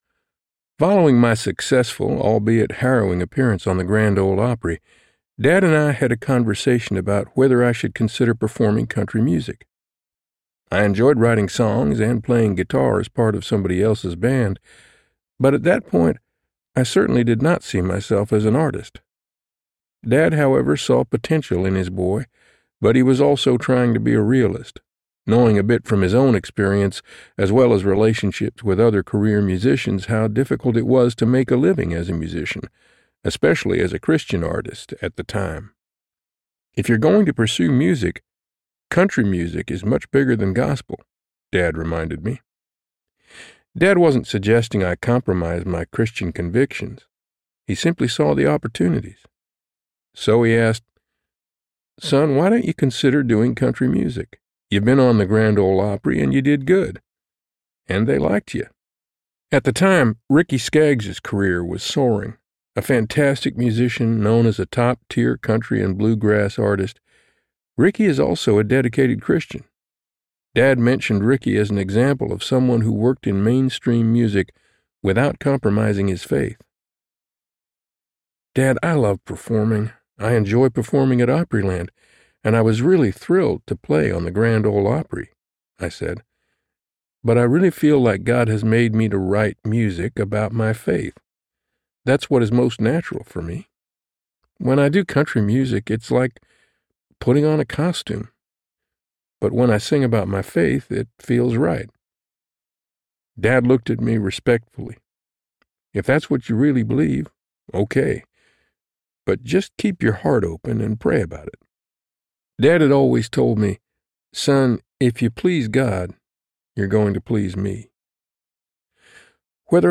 Between Heaven & the Real World Audiobook
Narrator
11.85 Hrs. – Unabridged